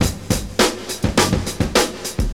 69 Bpm Drum Loop F# Key.wav
Free breakbeat - kick tuned to the F# note. Loudest frequency: 2360Hz
69-bpm-drum-loop-f-sharp-key-bqB.ogg